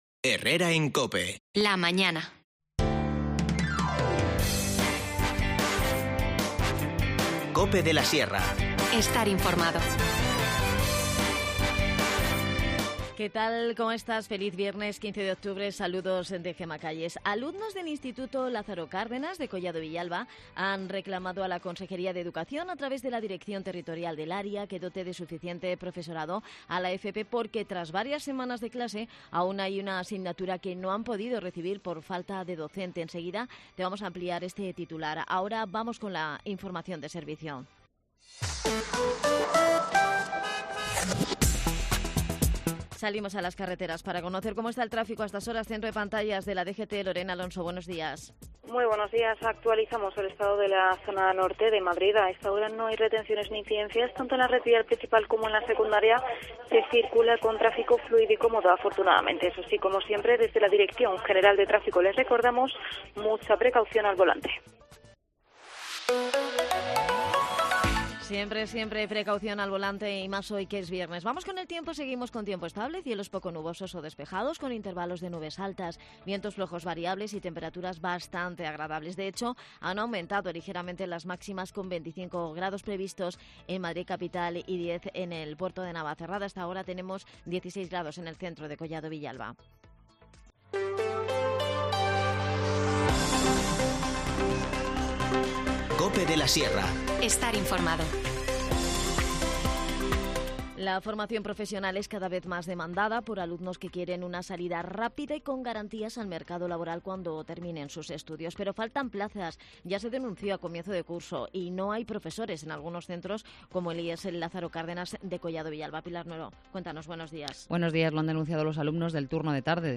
Valdemorillo retoma este sábado las salidas de senderismo. Lo hará para descubrir la Cascada del Purgatorio. En el espacio de Deportes de Herrera en COPE, nos lo cuenta Miguel Partida, concejal de Juventud.